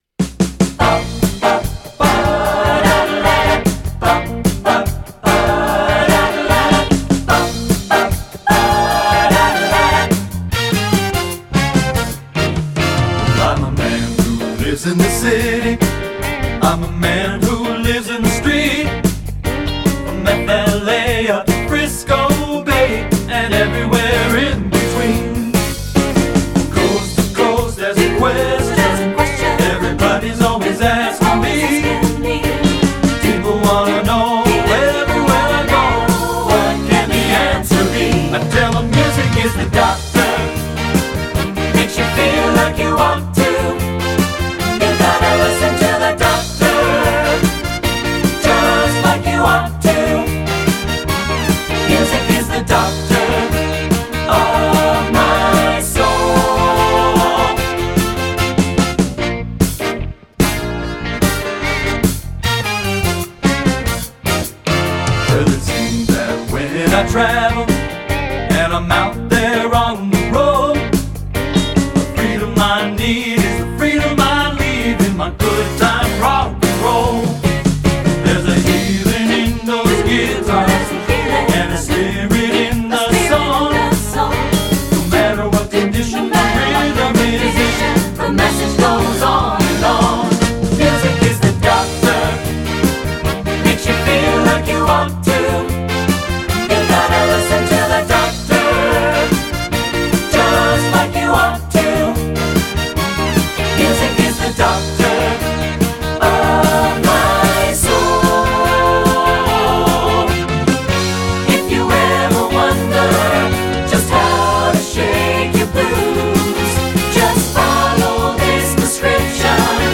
Voicing: TTB and Piano